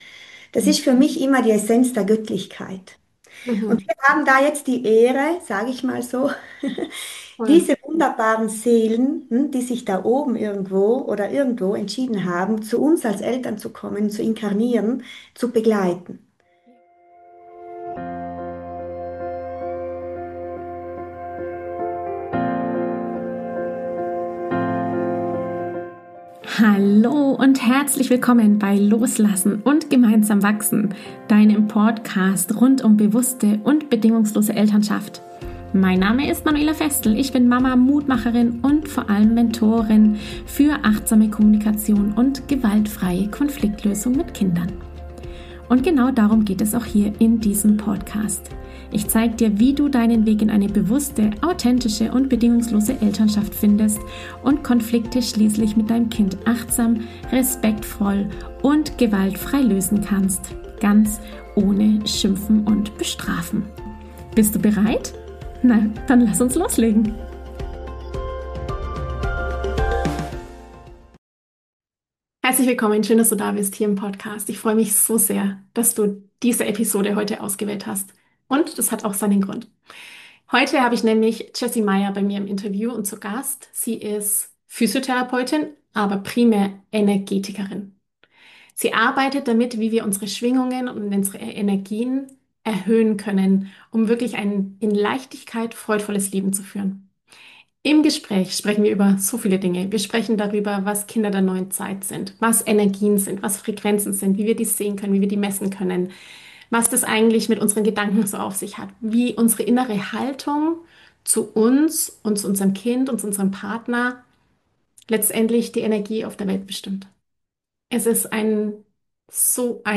Energiemedizin & Intuition in der Elternschaft - ein Interview